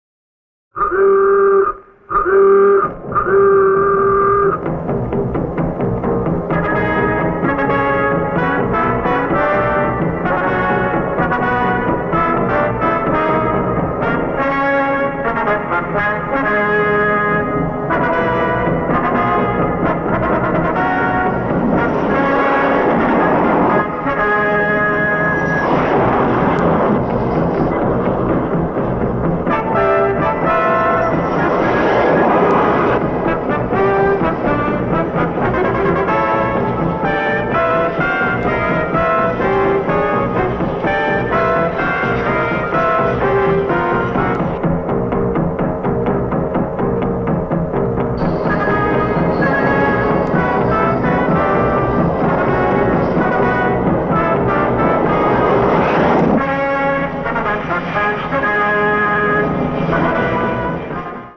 The bouncy score and its ear worm title theme